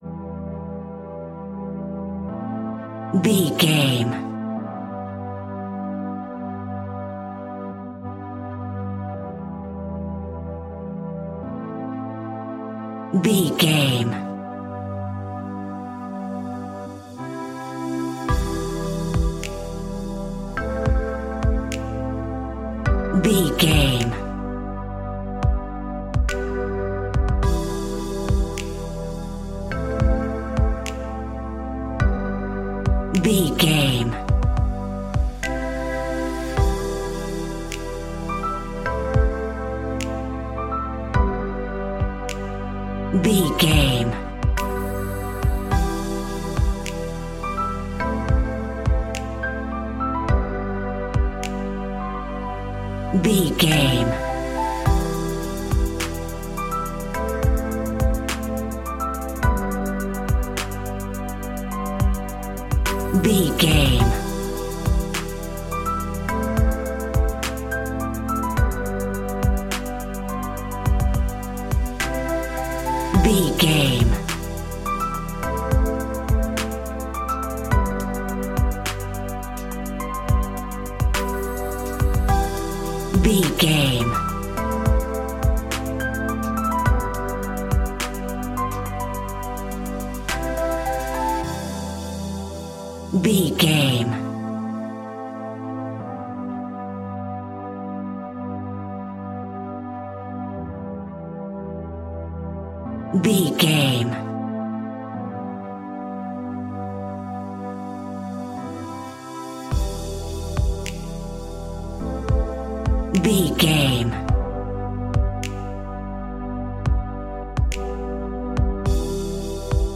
Aeolian/Minor
hip hop
chilled
laid back
groove
hip hop drums
hip hop synths
piano
hip hop pads